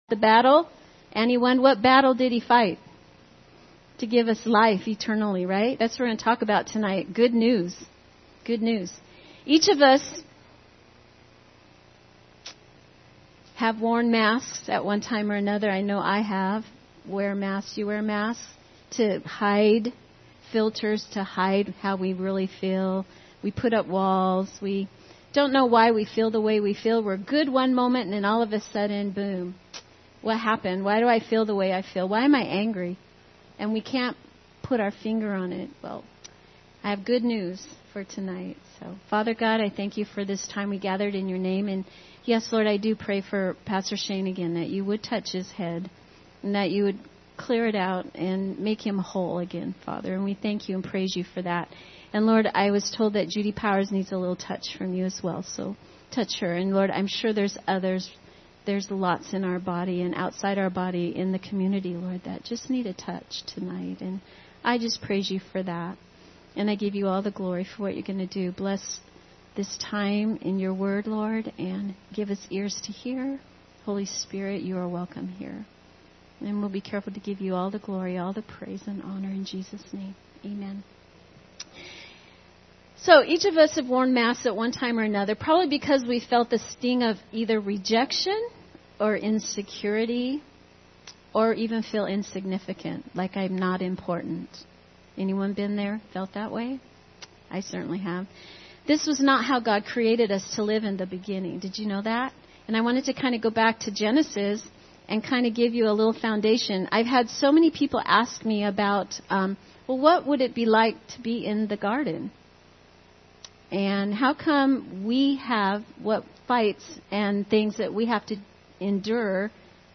Midweek Service October 19, 2022
Recent Sermons